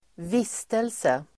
Uttal: [²v'is:telse]